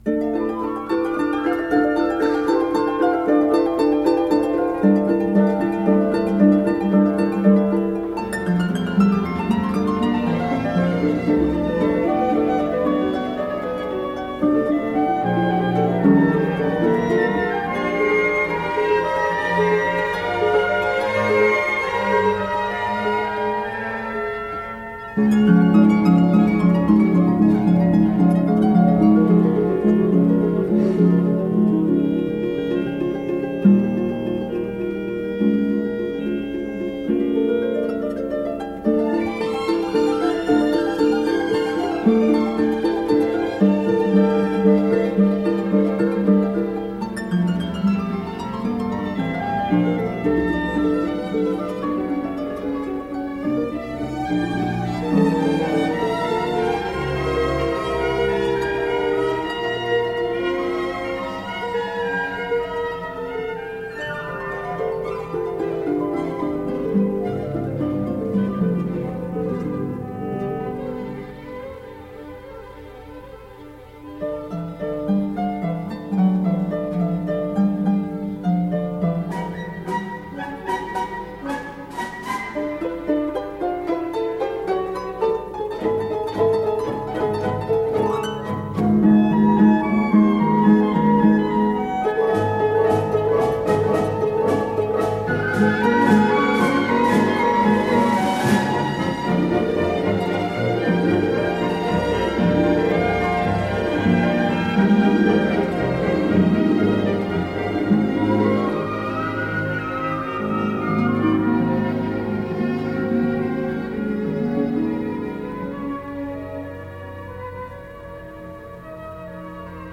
a concert performance